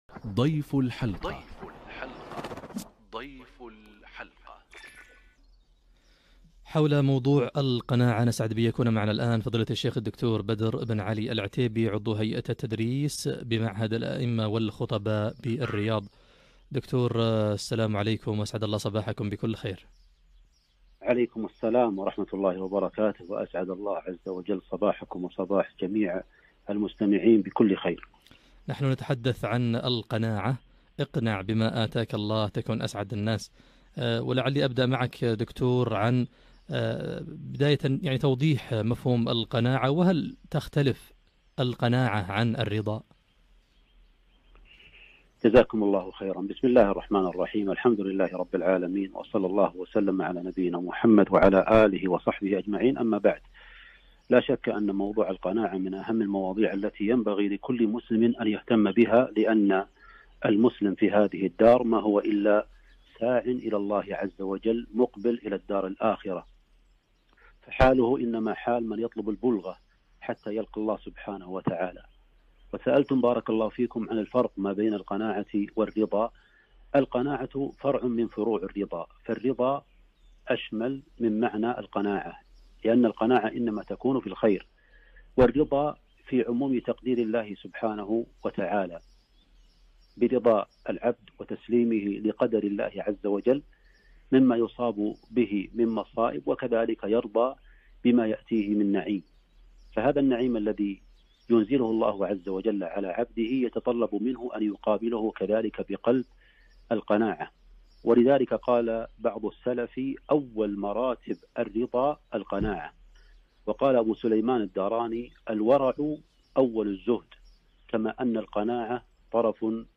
اقنع بما أتاك الله - كلمة عبر إذاعة القرآن الكريم